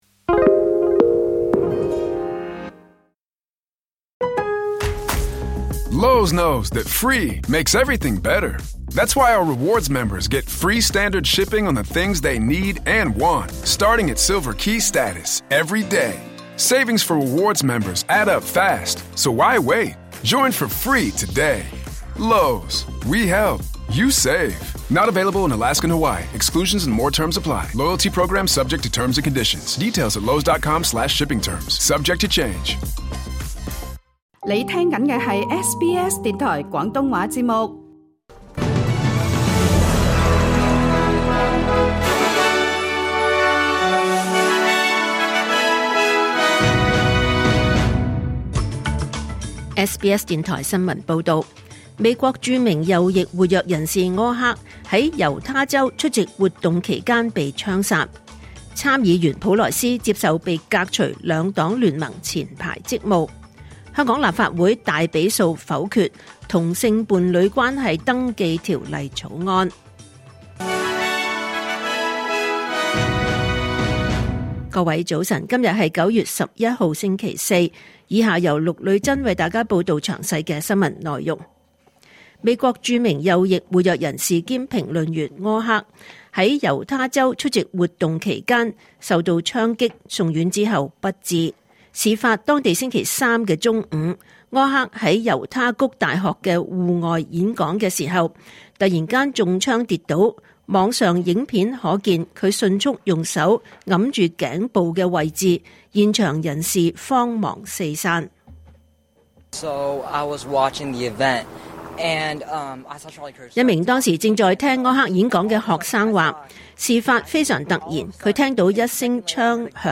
2025年9月11日 SBS 廣東話節目九點半新聞報道。